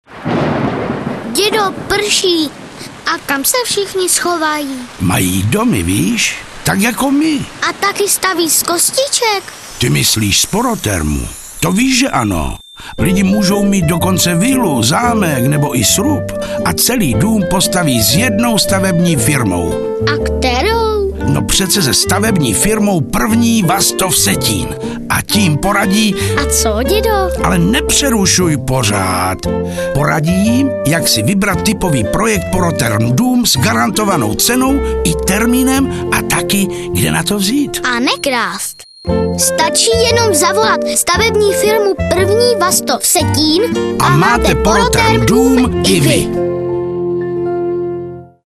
Například: pro záznam mluveného slova používáme americké lampové mikrofony Groove Tubes GT66, pro střih a postprodukci využíváme produkty firem Magix, Waves. iZotope ...
reklamní spot s příběhem